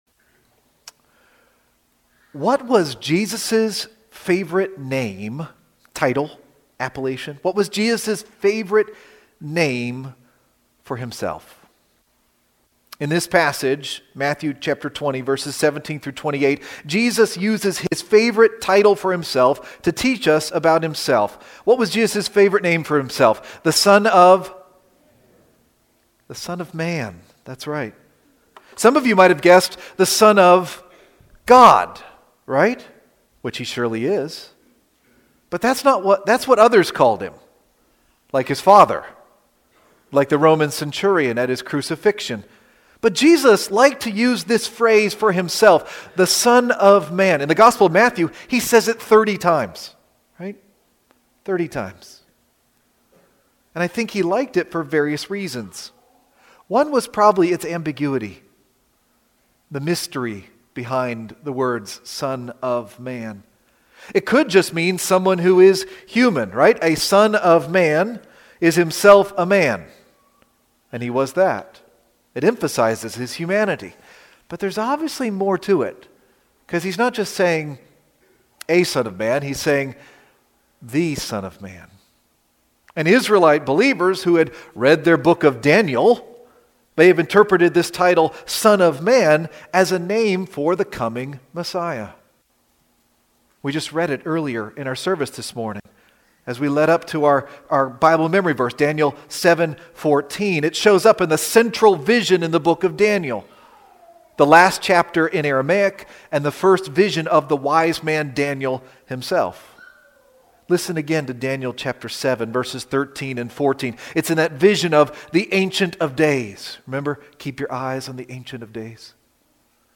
The Son of Man :: April 6, 2025 - Lanse Free Church :: Lanse, PA